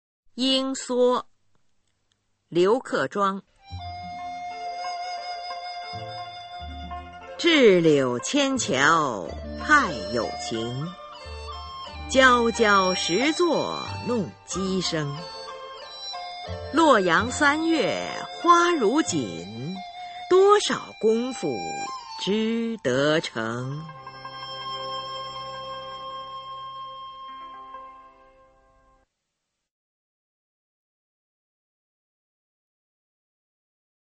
[宋代诗词朗诵]刘克庄-莺梭 古诗词诵读